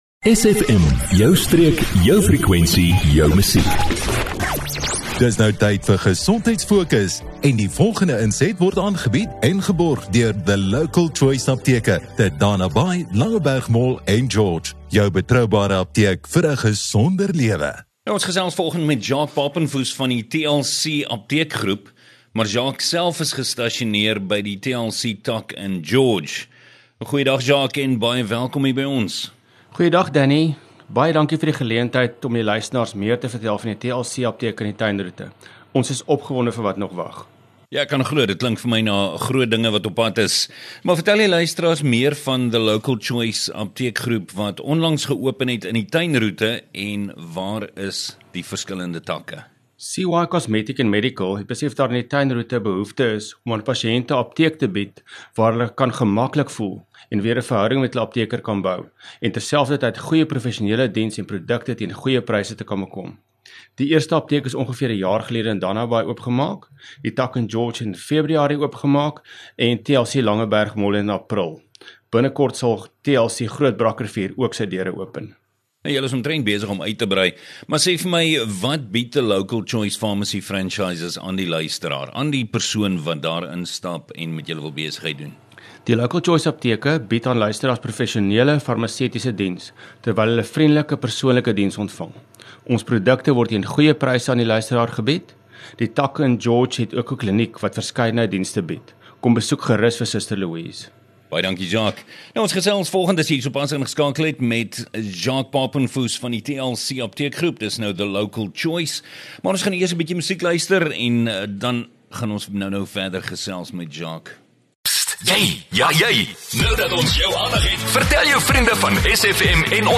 12 Apr GesondheidFokus: The Local Choice apteek onderhoud 12 April 2024